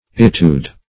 'Etude \['E]`tude"\ ([asl]`t[.u]d"), n. [F. See Study.]